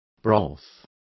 Complete with pronunciation of the translation of broths.